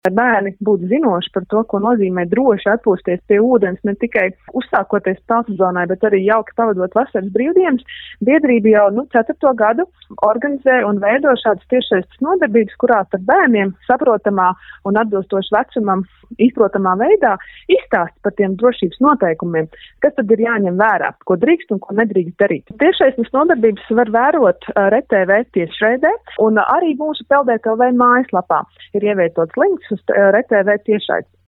Saruna